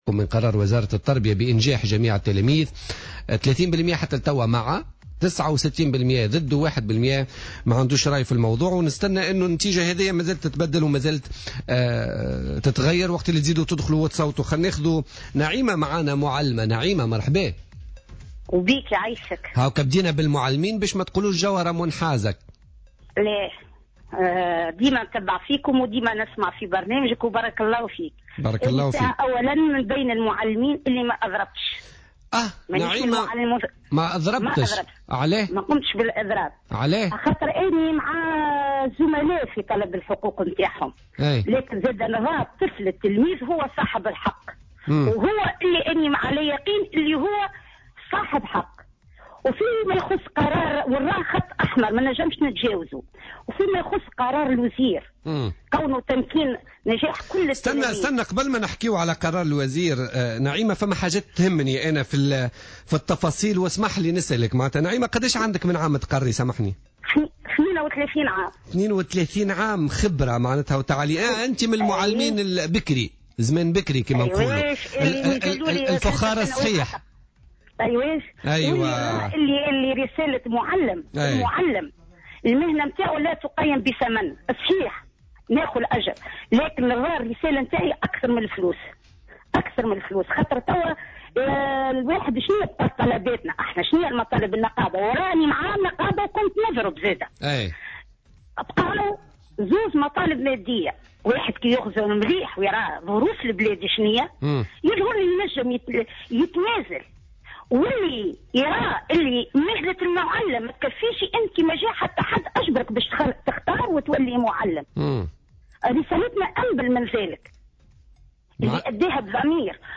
أكدت معلمة بمدرسة ابتدائية في مداخلة لها في بوليتيكا اليوم الجمعة 12 جوان 2015 أنها لم تشارك في مقاطعة الامتحانات معتبرة أن التلميذ هو صاحب الحق الوحيد في كل ما يحدث وهو خط احمر لا يمكن تجاوزه .